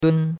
dun1.mp3